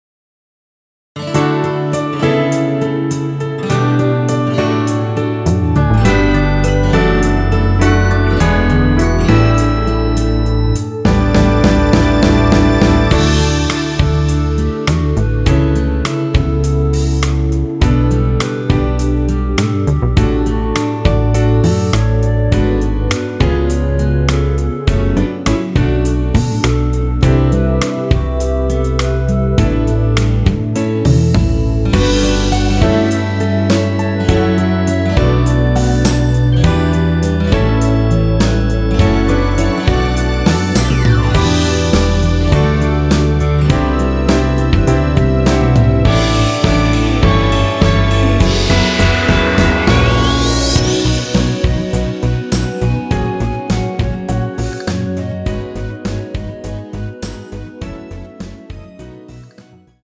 원키 멜로디 포함된 MR입니다.(미리듣기 확인)
앞부분30초, 뒷부분30초씩 편집해서 올려 드리고 있습니다.
(멜로디 MR)은 가이드 멜로디가 포함된 MR 입니다.